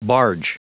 Prononciation du mot barge en anglais (fichier audio)
Prononciation du mot : barge